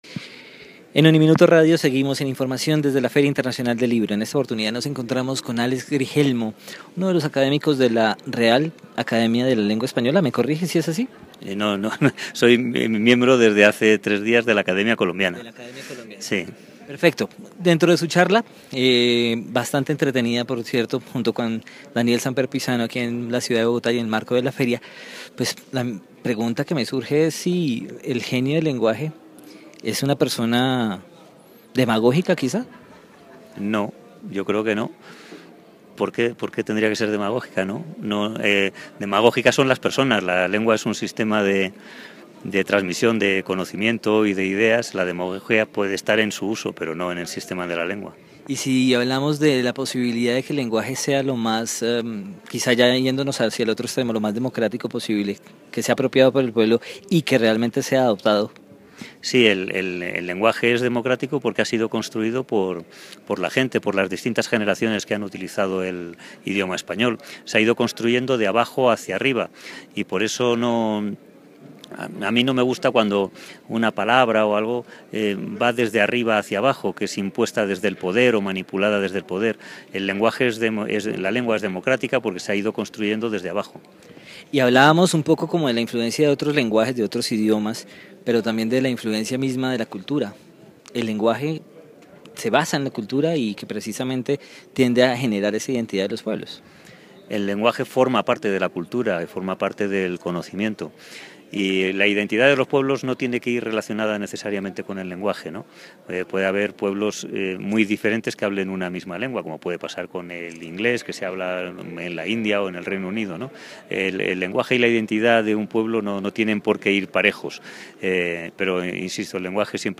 Durante la 31 Feria Internacional del Libro de Bogotá, habló sobre el genio del idioma y la gramática descomplicada.
‘El lenguaje sí es democrático, porque ha sido construido por la gente, por generaciones que han usado el español y se ha ido construyendo de abajo hacía arriba’. comentó Grijelmo en una conversación con  Daniel Samper Pizano, sobre  el tema de la lengua,